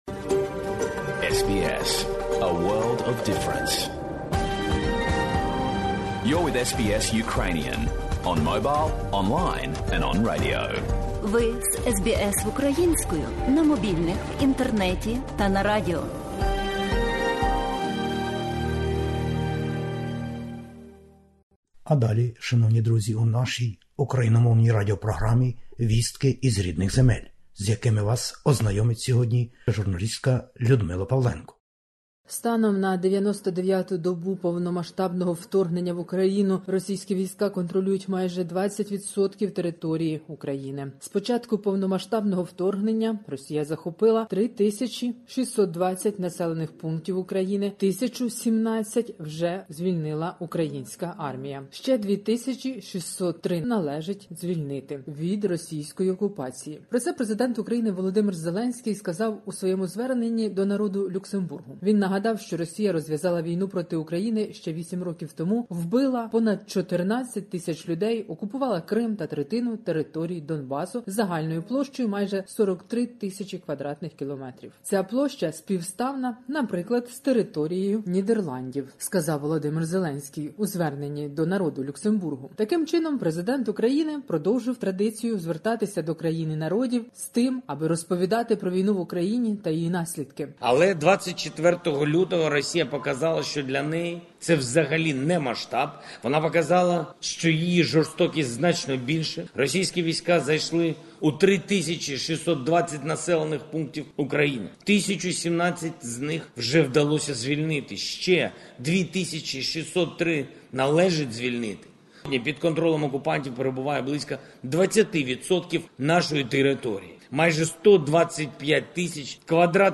Добірка новин із героїчної України. Війна - Росія захопила і контролює майже 20 відсотків території України. Російські війська вивезли до Росії щонайменше 234 000 дітей із України.